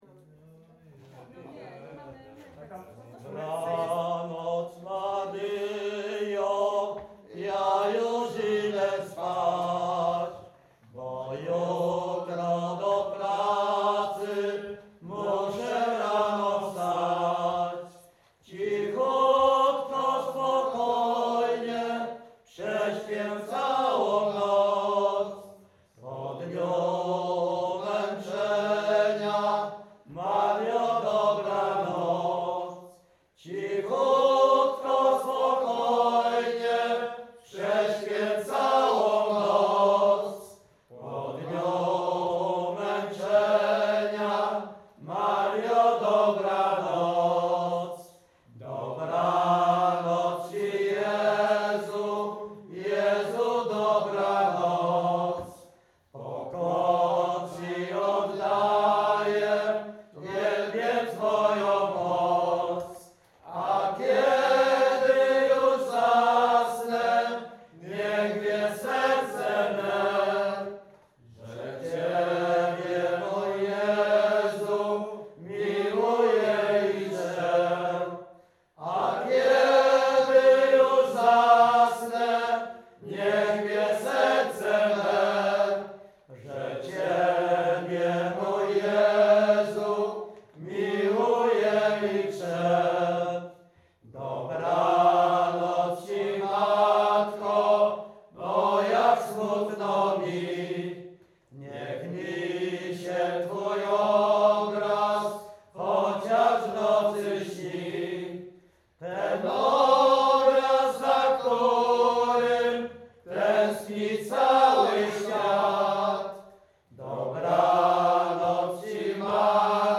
Śpiewacy z Ruszkowa Pierwszego
uczestnicy zgromadzenia śpiewaczego
Wielkopolska, powiat kolski, gmina Kościelec, wieś Ruszków Pierwszy
podczas zgromadzenia śpiewaczego w Poznaniu
Array nabożne katolickie maryjne